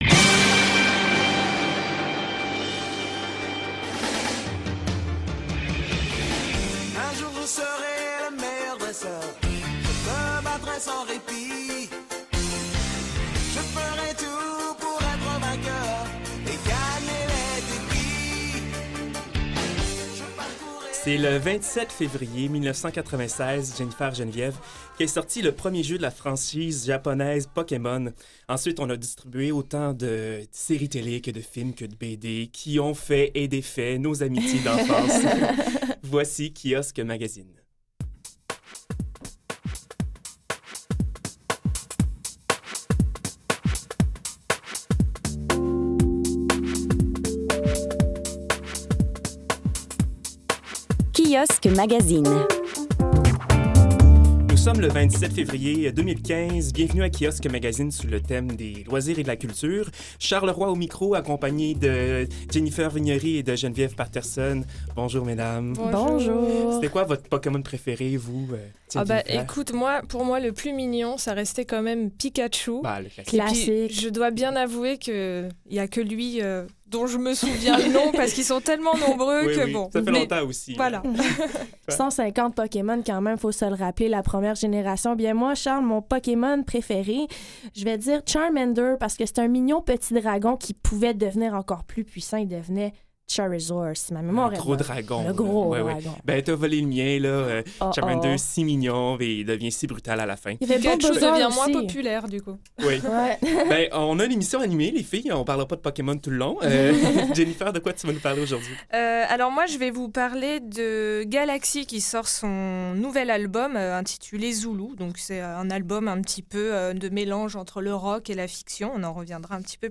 Aujourd'hui, le meilleur des magazines culture et loisirs sous forme de revue de presse